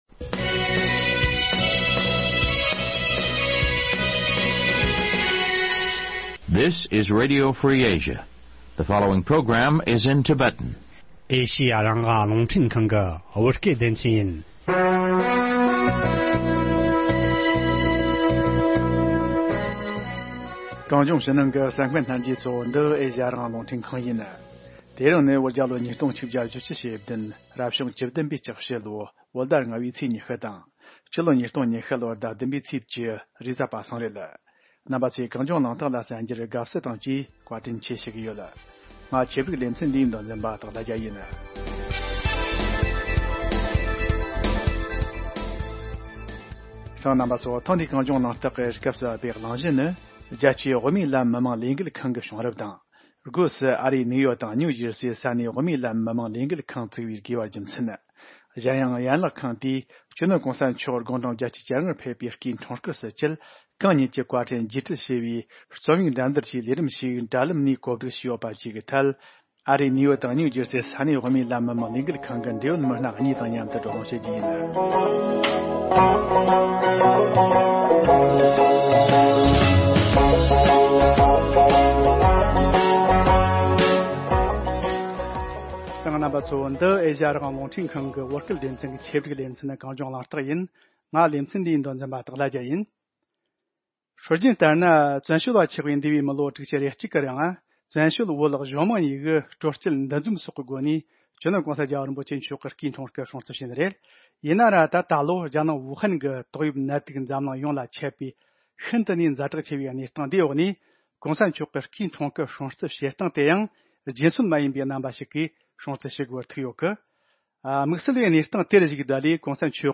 བགྲོ་གླེང་བྱས་པར་གསན་རོགས་གནོངས།།